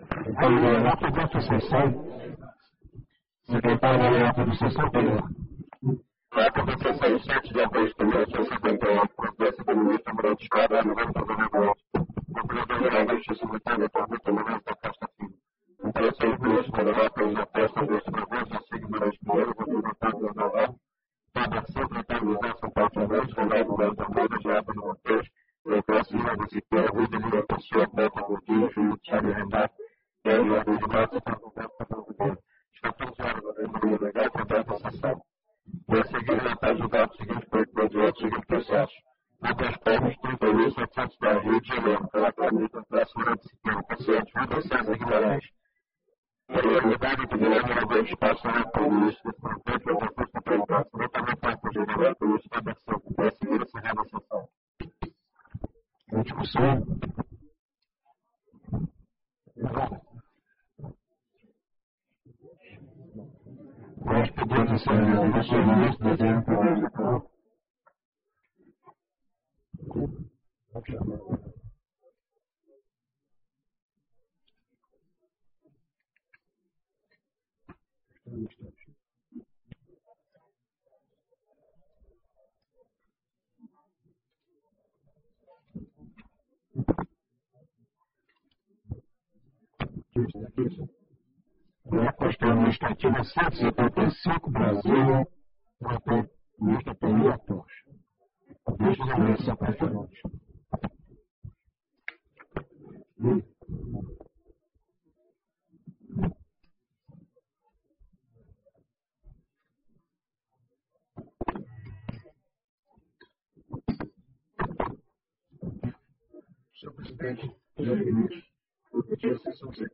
Ata da 56ª Sessão (Extraordinária), em 08/08/1978